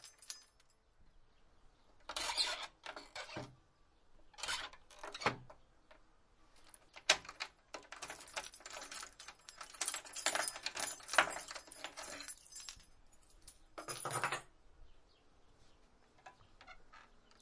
描述：木质百叶窗内门与古董手柄锁打开关闭关闭框架拨浪鼓和手柄转动吱吱声结束各种在offmic int透视
标签： 运算恩 处理 关闭 快门 仿古
声道立体声